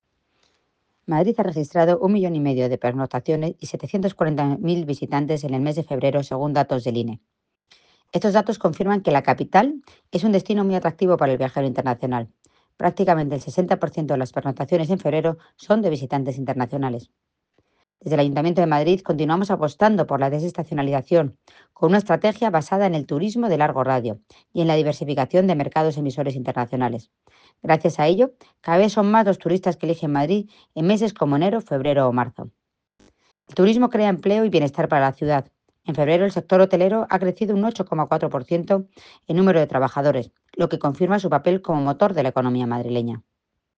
Declaraciones-de-la-delegada-de-Turismo-Almudena-Maillo.ogg